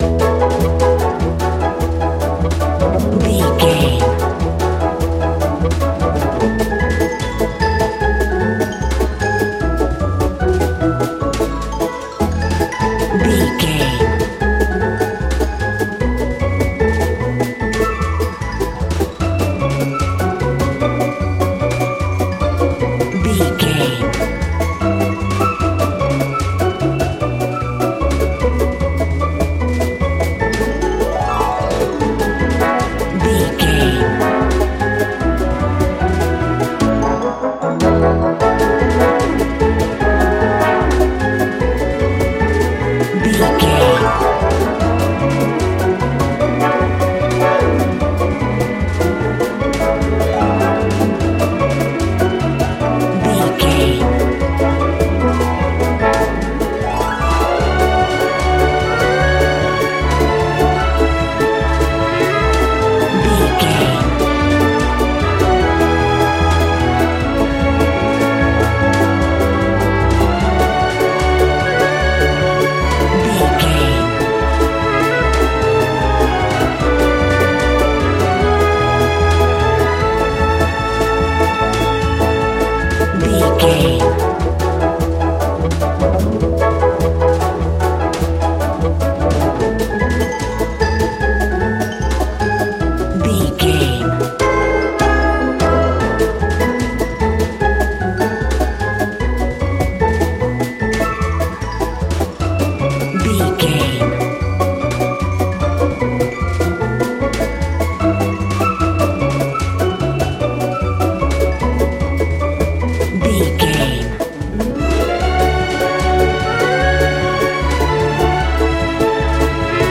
Ionian/Major
Fast
cheerful/happy
frantic